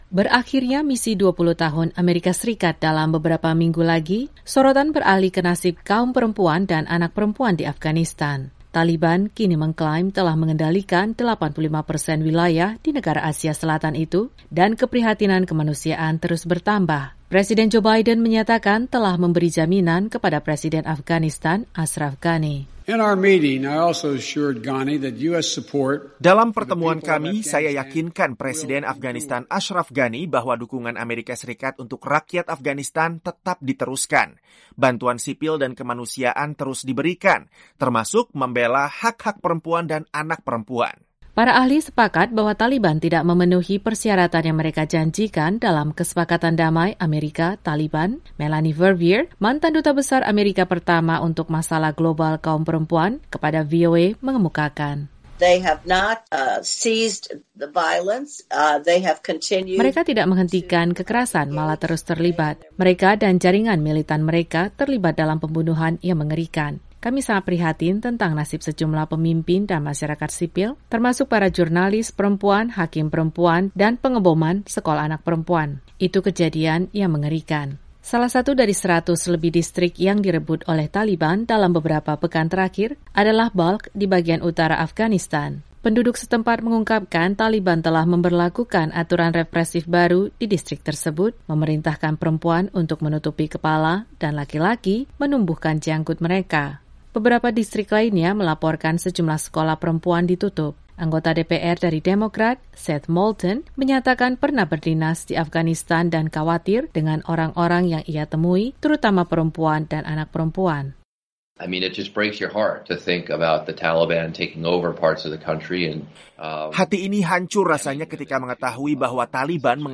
Presiden Joe Biden hari Kamis (8/7) mengumumkan misi AS di Afghanistan berakhir pada 31 Agustus 2021, namun masih ada kekhawatiran terhadap nasib warga sipil Afghanistan, terutama kaum perempuan. Laporan VOA